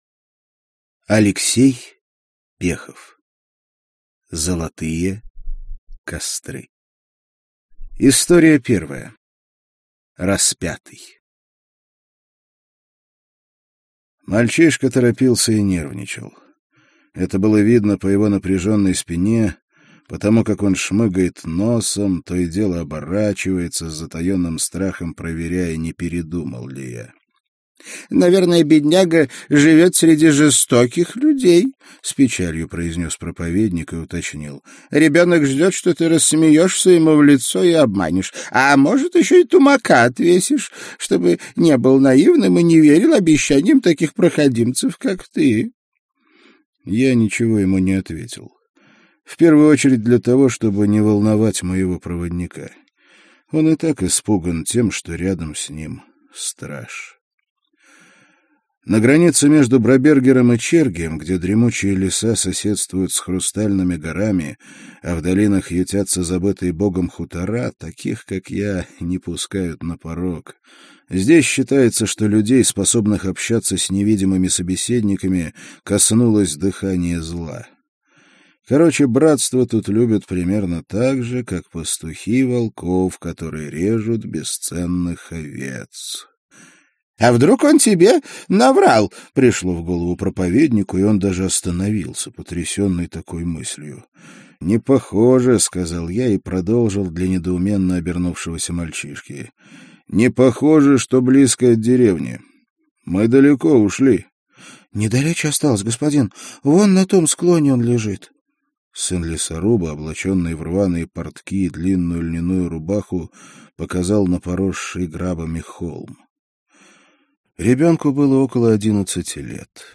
Аудиокнига Золотые костры - купить, скачать и слушать онлайн | КнигоПоиск